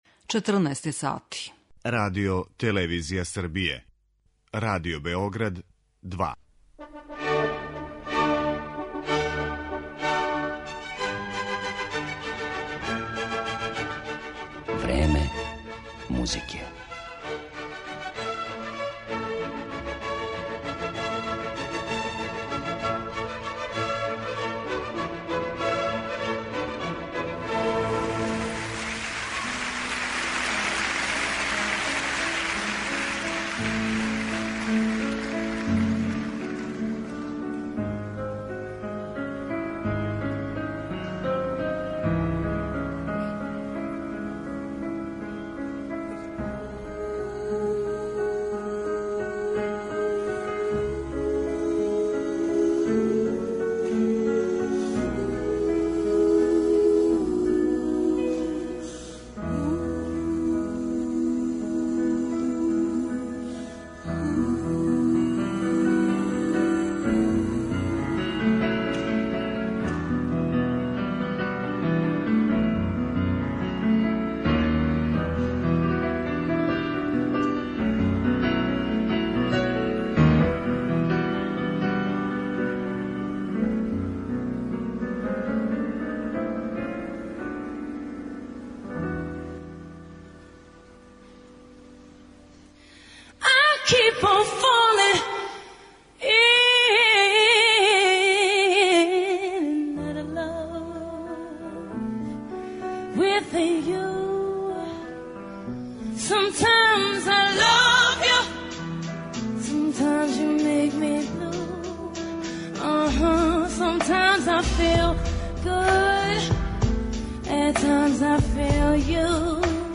предавања